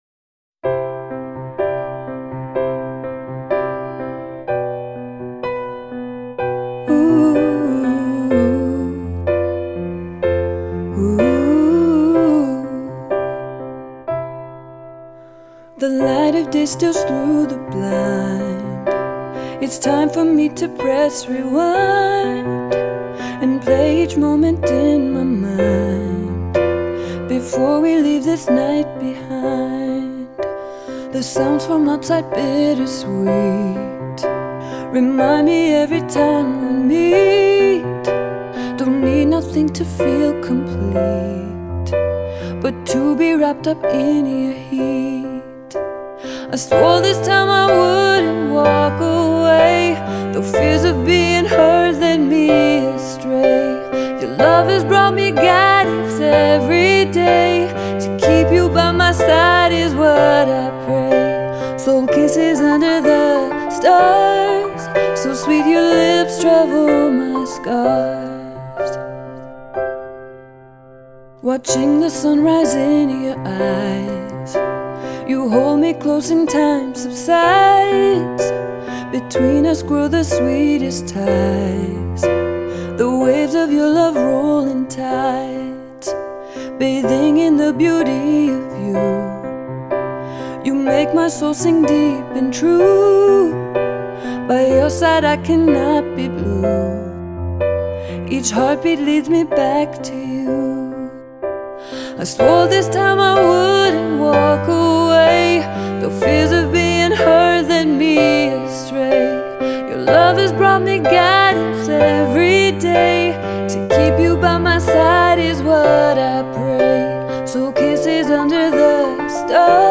Je fais toujours de belles chansons mélodieuses, épaulé de mon arrangeur et ingénieur de son talentueux.